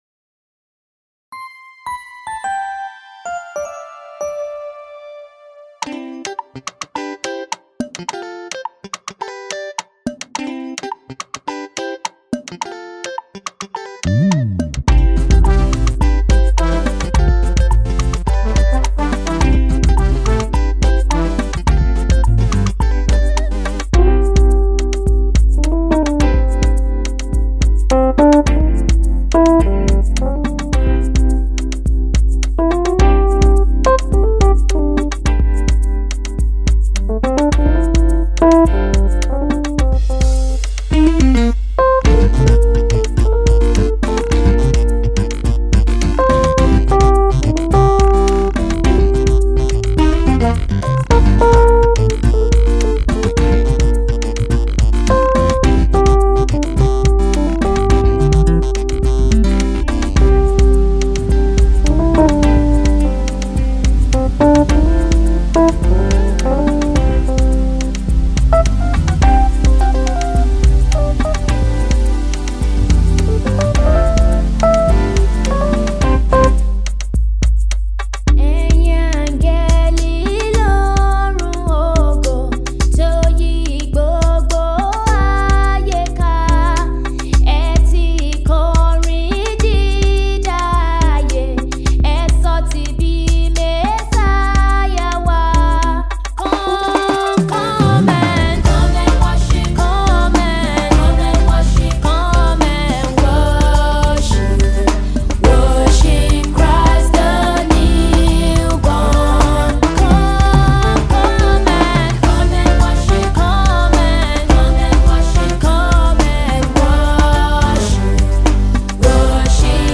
groovy yet soul-inspiring gospel jazz music for the season